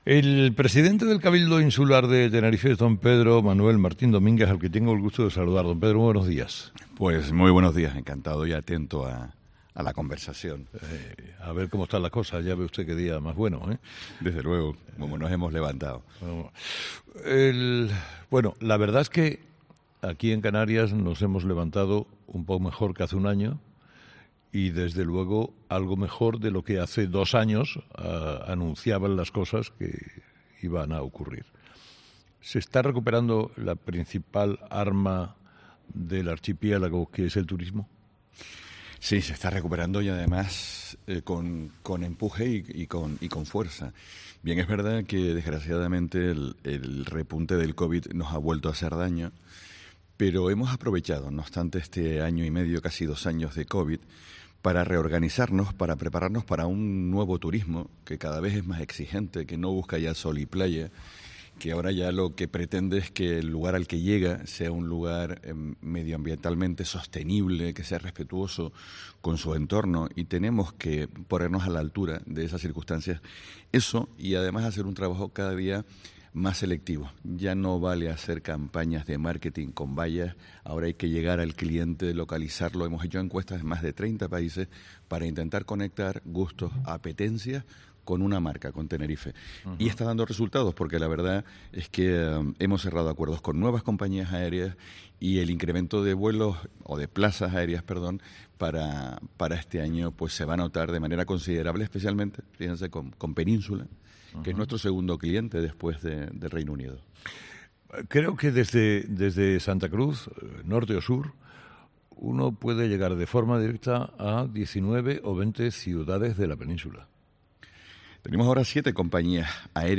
El presidente del Cabildo de Tenerife habla de los nuevos poryectos turísticos en los que están trabajando para atraer a la gente a las Islas